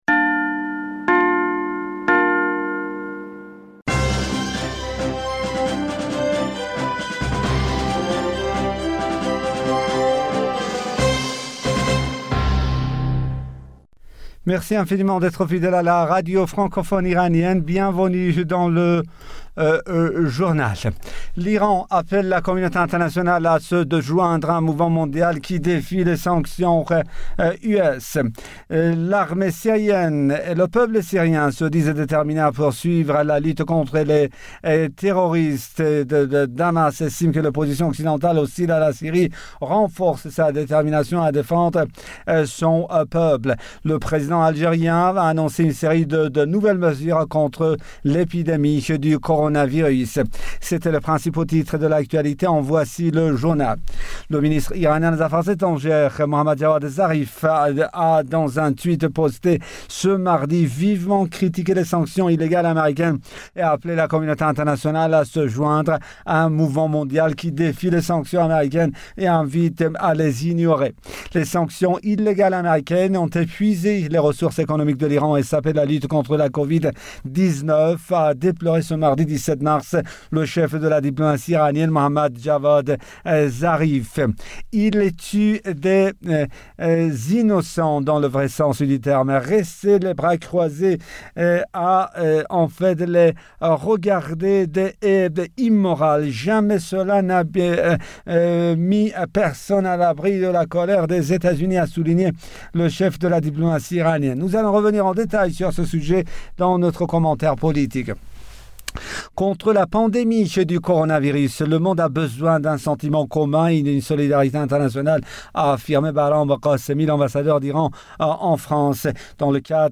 Bulletin d'information du 18 mars 2020